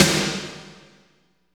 52.06 SNR.wav